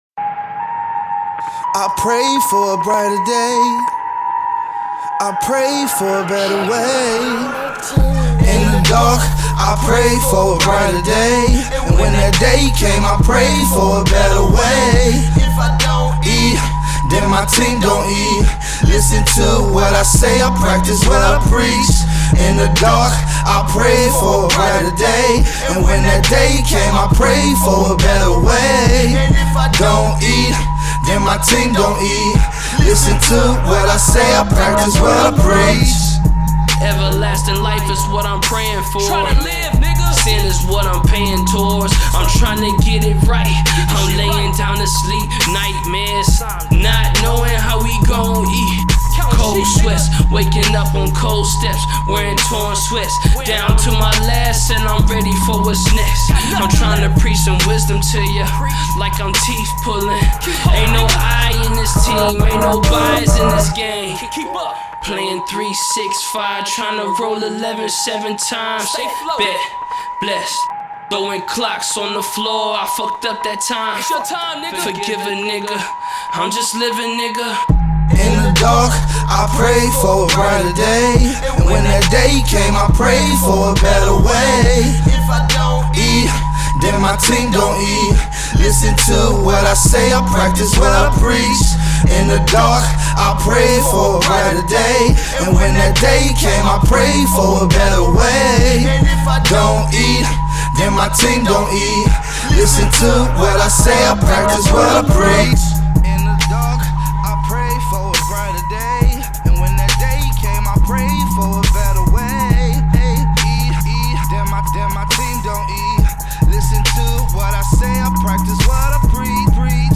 Hiphop
Hard Core Hip Hop